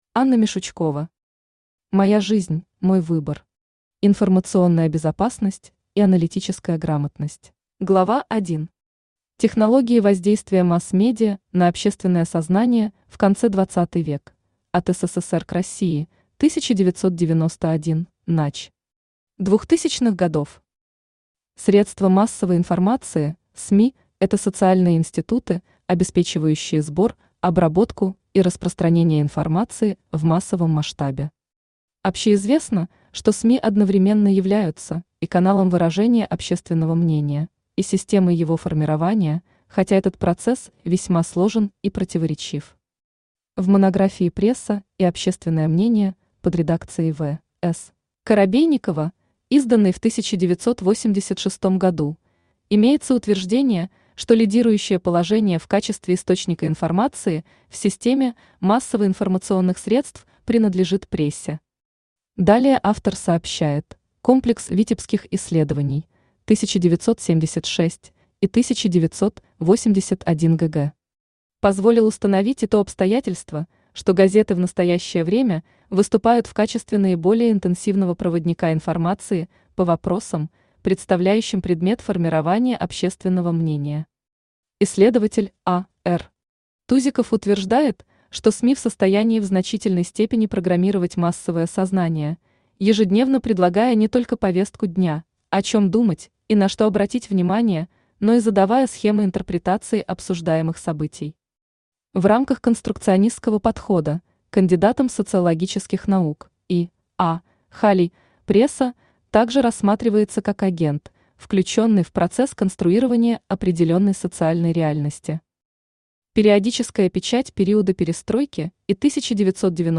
Информационная безопасность и аналитическая грамотность Автор Анна Владимировна Мишучкова Читает аудиокнигу Авточтец ЛитРес.